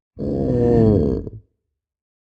Minecraft Version Minecraft Version 1.21.5 Latest Release | Latest Snapshot 1.21.5 / assets / minecraft / sounds / mob / sniffer / idle10.ogg Compare With Compare With Latest Release | Latest Snapshot